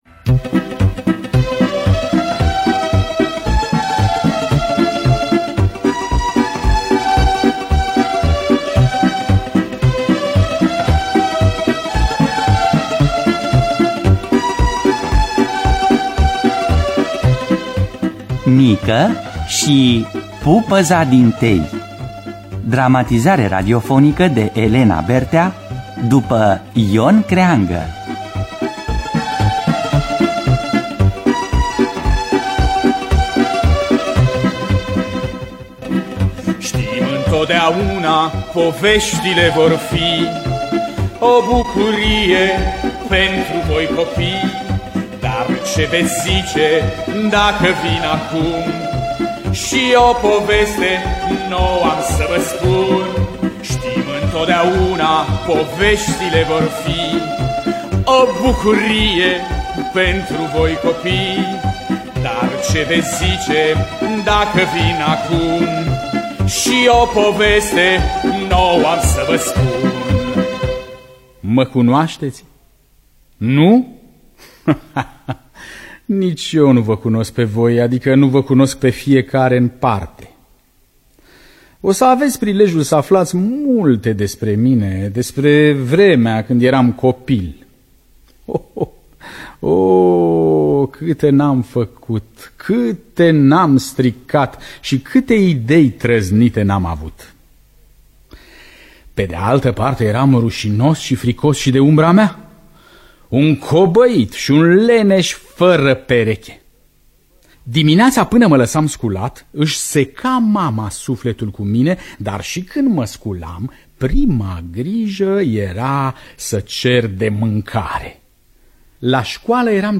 Nică și pupăza din tei de Ion Creangă – Teatru Radiofonic Online
Dramatizarea radiofonică